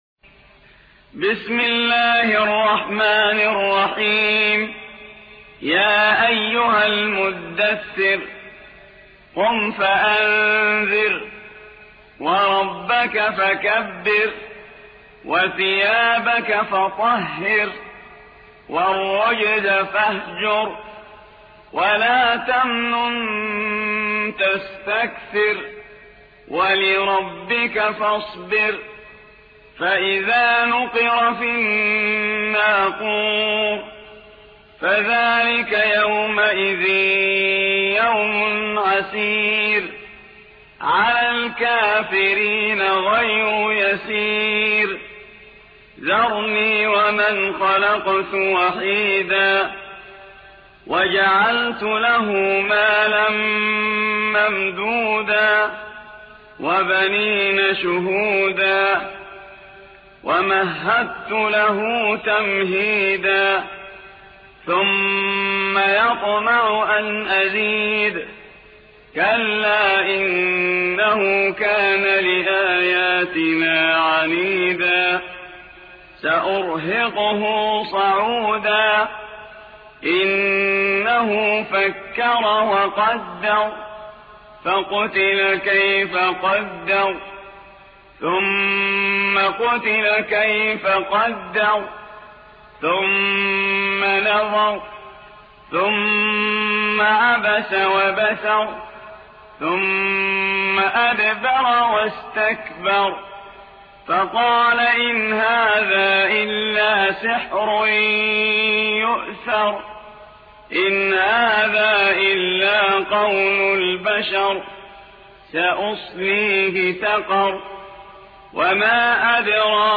74. سورة المدثر / القارئ